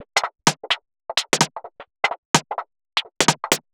Index of /musicradar/uk-garage-samples/128bpm Lines n Loops/Beats
GA_BeatEnvC128-07.wav